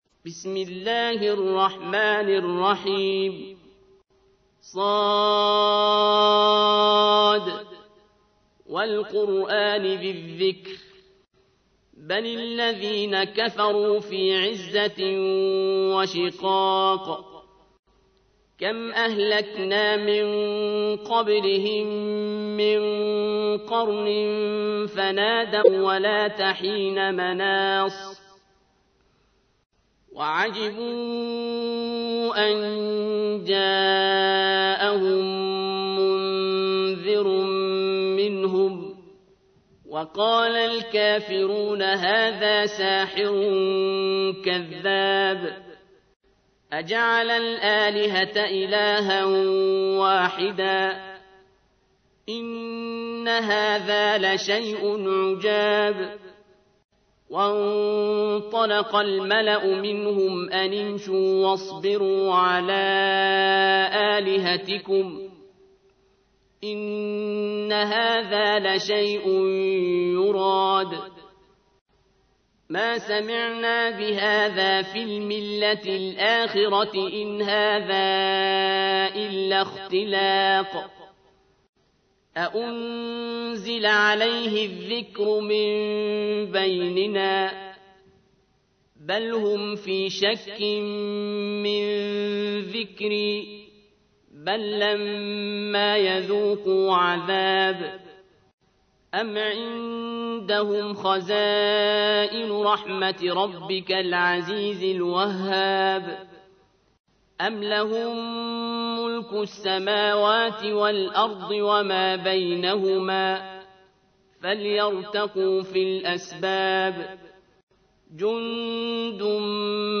تحميل : 38. سورة ص / القارئ عبد الباسط عبد الصمد / القرآن الكريم / موقع يا حسين